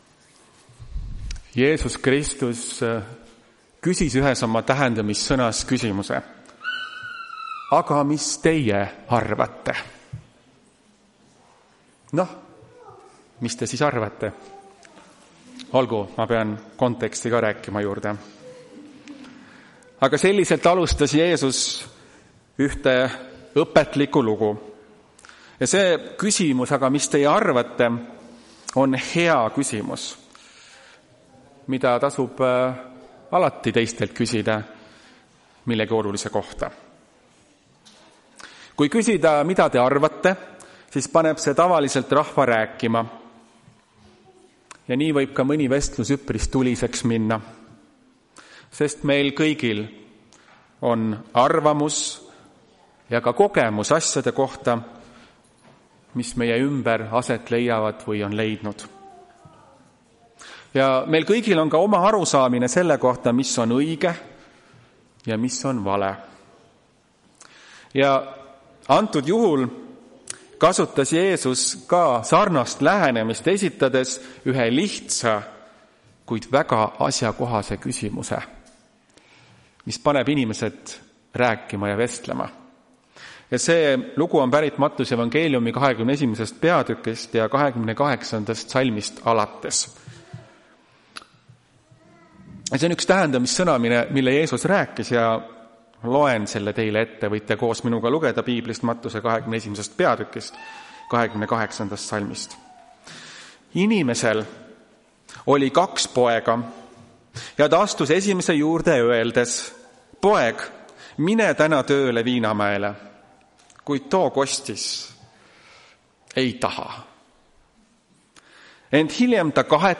Tartu adventkoguduse 24.05.2025 teenistuse jutluse helisalvestis.